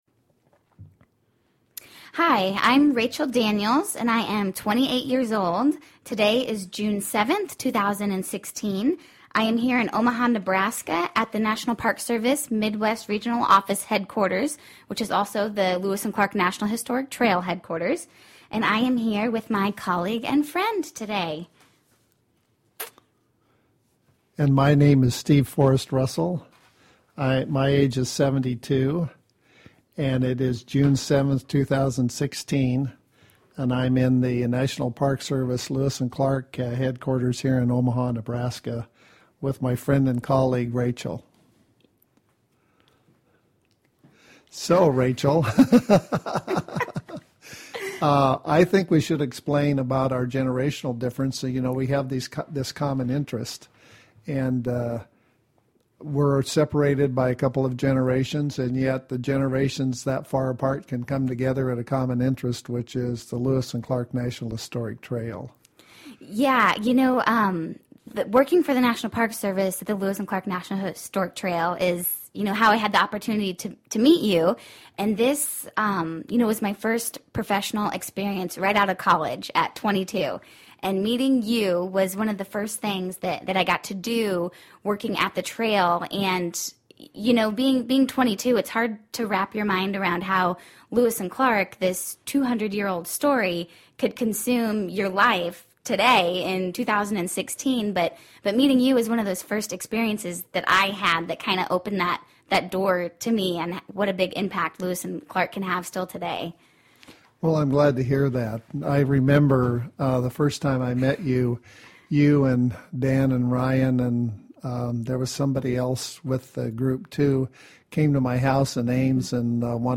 StoryCorps Interview